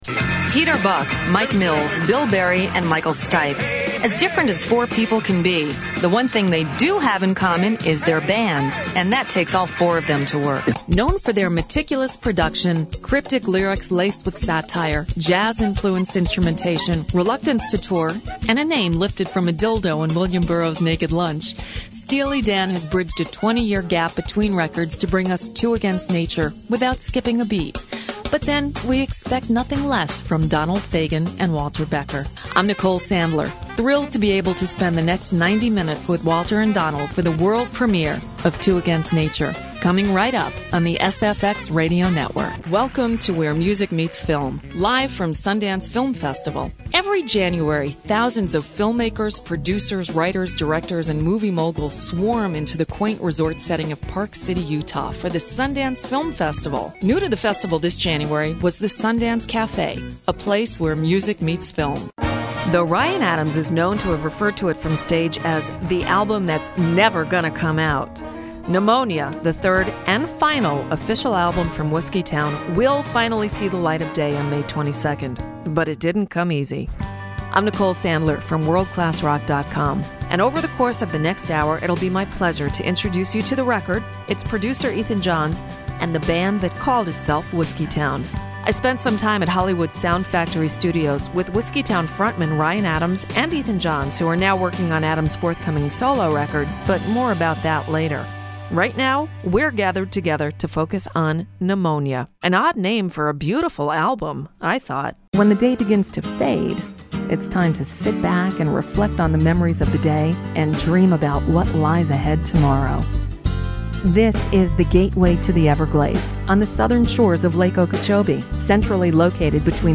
Video Narration